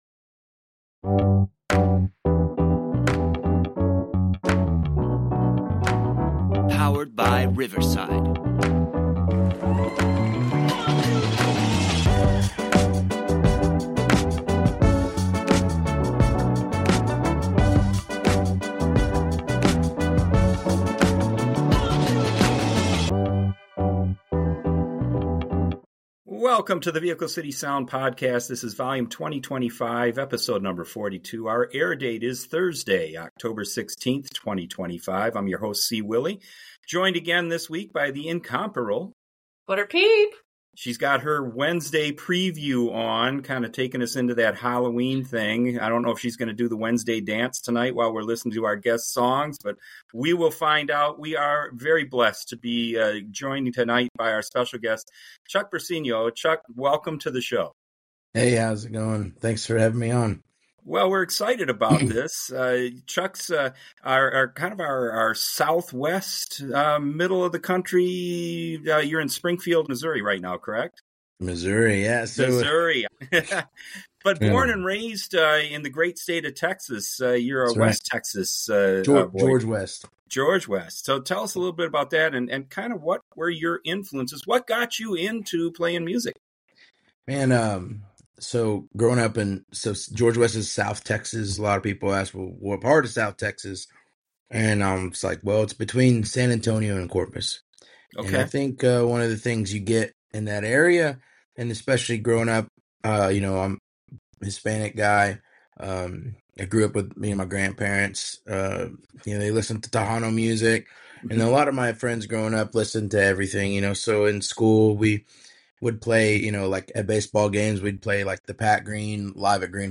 authentic Country and Americana